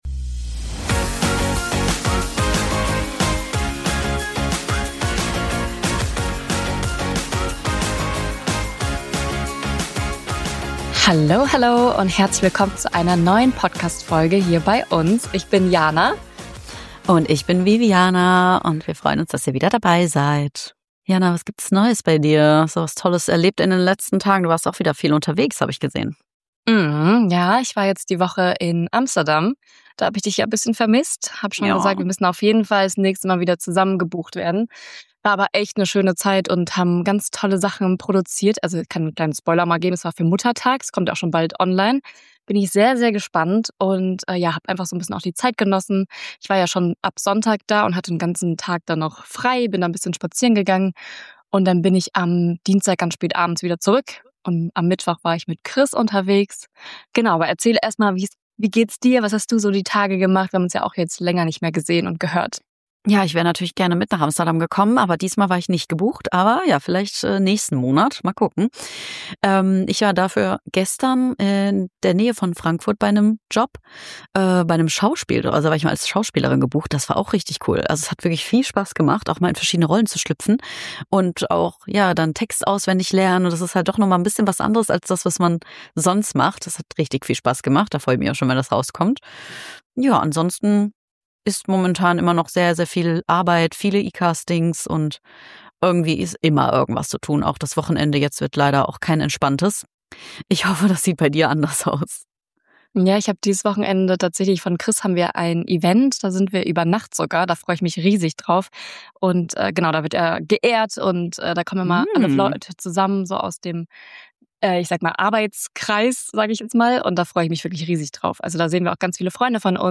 Ein ehrliches Gespräch zwischen Neugier, Unsicherheit und ganz viel Gedankensalat.